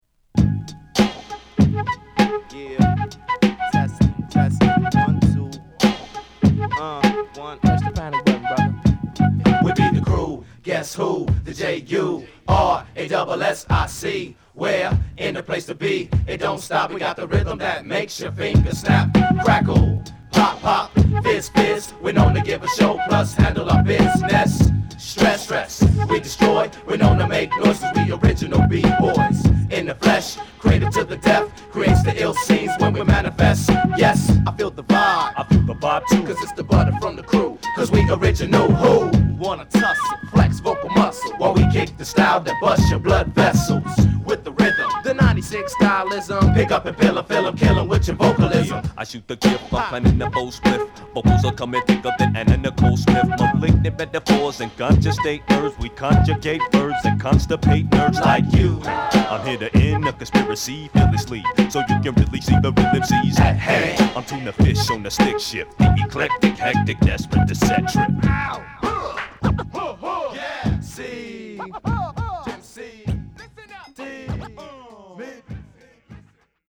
フルートのフレーズを効果的にサンプリングしたスモーキーなビート上で繰り広げられるオールドスクールマナーな掛け合いは必聴！